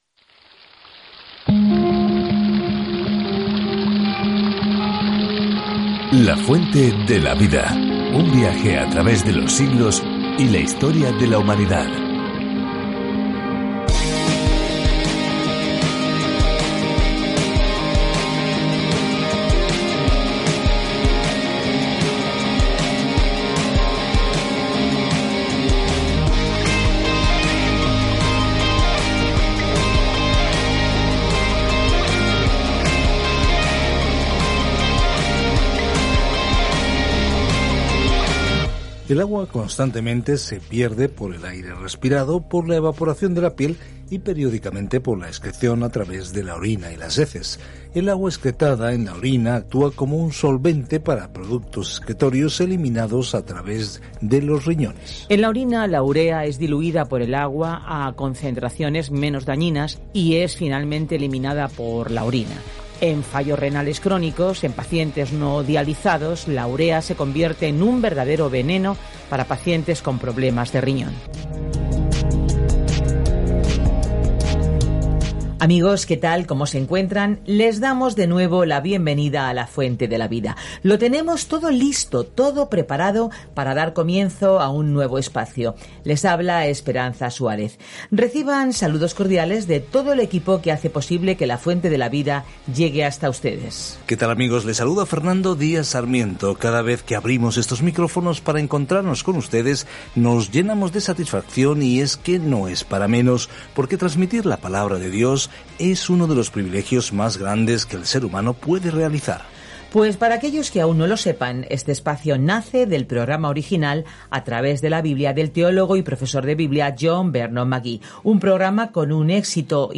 Scripture Revelation 6:1 Day 20 Start this Plan Day 22 About this Plan El Apocalipsis registra el final de la amplia línea de tiempo de la historia con la imagen de cómo finalmente se abordará el mal y cómo el Señor Jesucristo gobernará con toda autoridad, poder, belleza y gloria. Viaja diariamente a través del Apocalipsis mientras escuchas el estudio en audio y lees versículos seleccionados de la palabra de Dios.